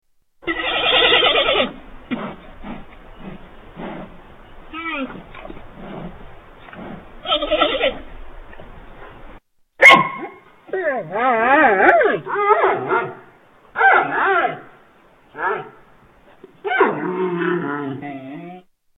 Giant Panda sound